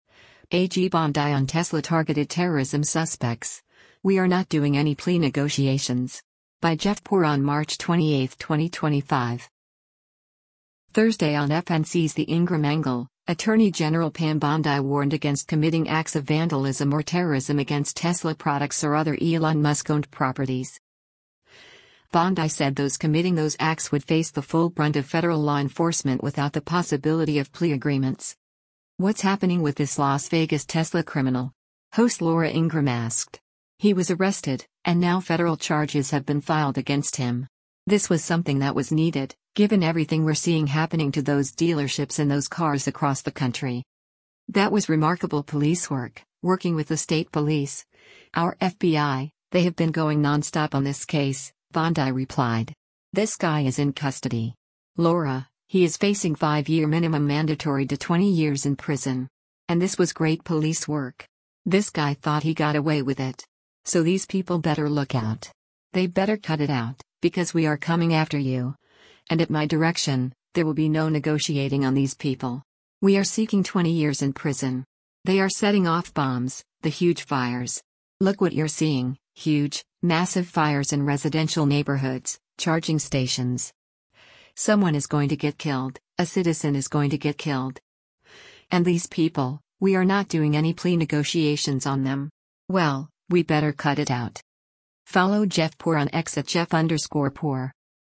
Thursday on FNC’s “The Ingraham Angle,” Attorney General Pam Bondi warned against committing acts of vandalism or terrorism against Tesla products or other Elon Musk-owned properties.